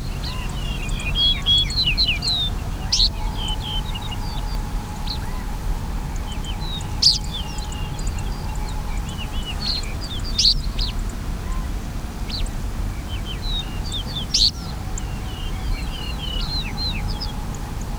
Yellow-breasted Bunting
VOICE Call a thin, sharp tick.
YB_Bunting_02call_Yakutsk.wav